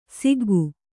♪ siggu